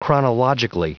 Prononciation du mot chronologically en anglais (fichier audio)
Prononciation du mot : chronologically